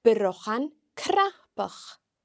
Let’s have a look at how the consonants cn are pronounced in Gaelic.